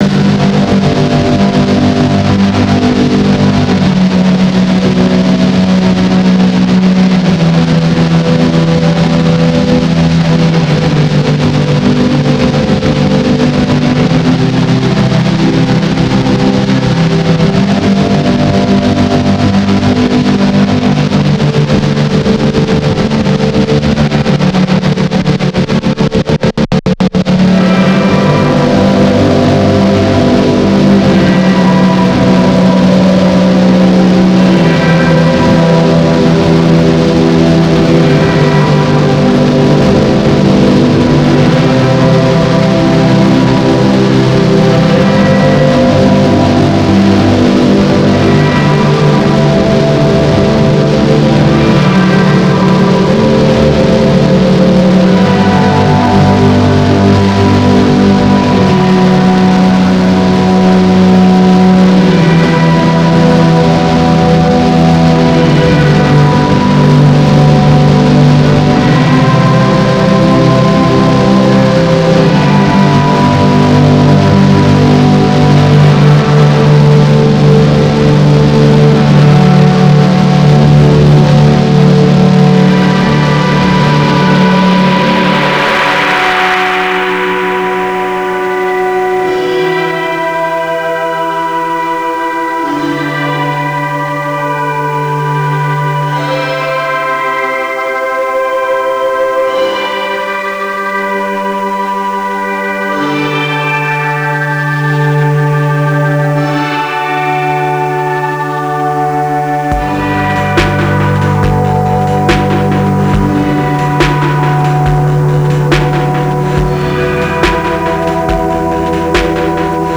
shoegaze, electronic, noise, harsh noise,